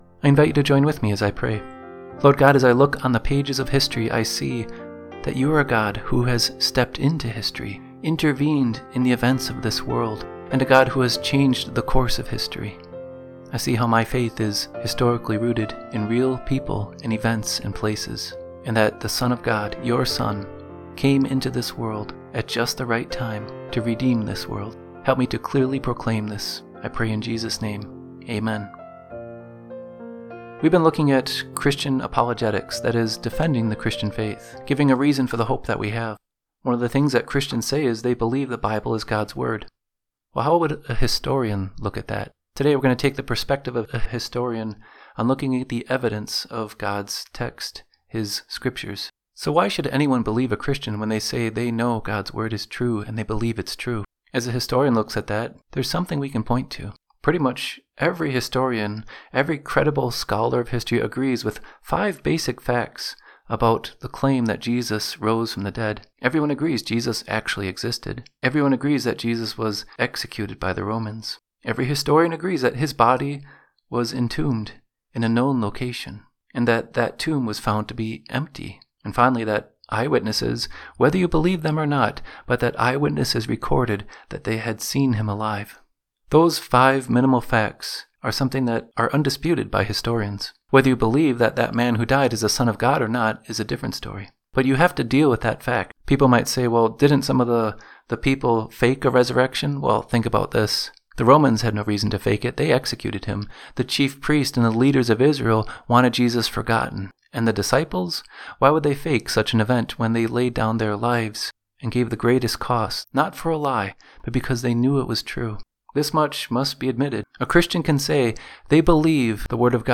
Listen to a brief devotion based on our study Apologetics 3) History and the Resurrection